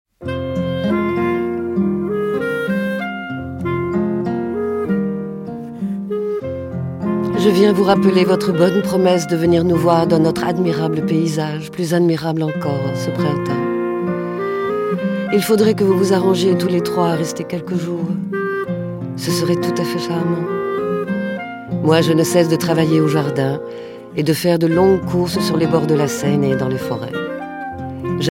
clarinette, cor de basset